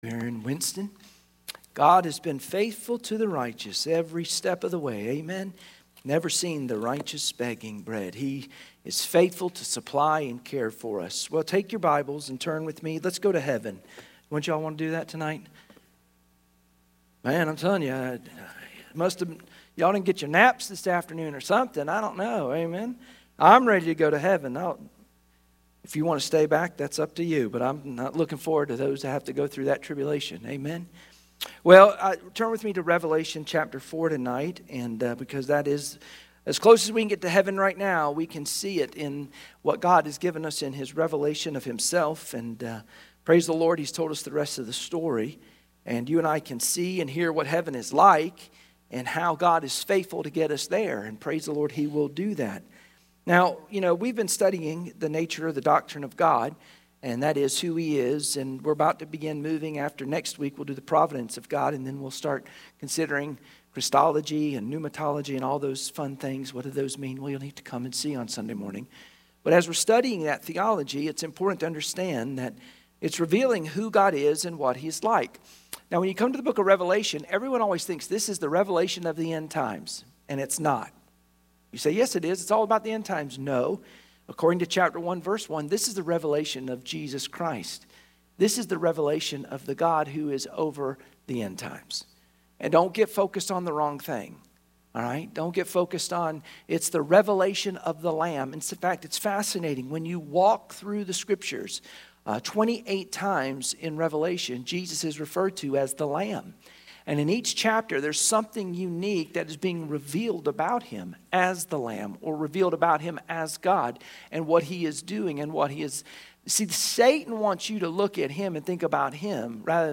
Sunday Evening Service Passage: Revelation 4-5 Service Type: Sunday Evening Worship Share this